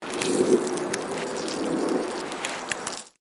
Free SFX sound effect: Shapeshifter.